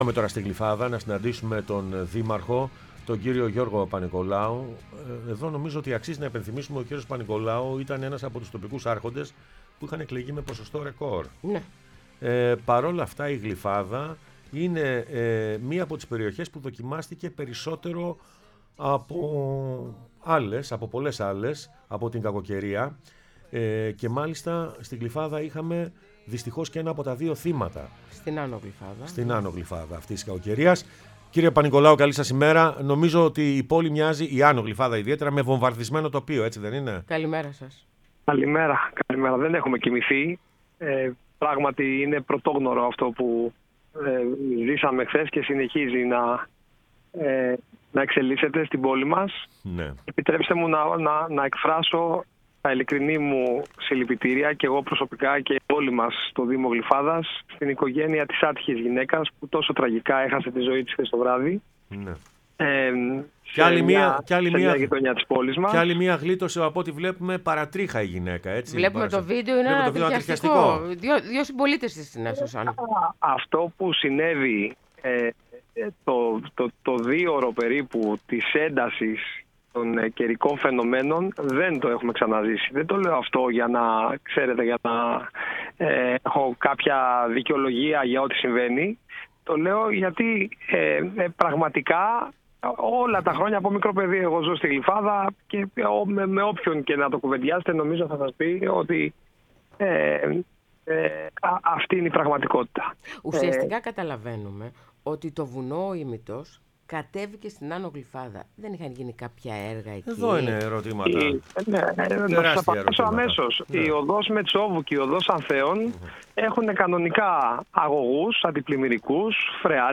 Ο Γιώργος Παπανικολάου, δήμαρχος Γλυφάδας, μίλησε στην εκπομπή «Πρωινές Διαδρομές»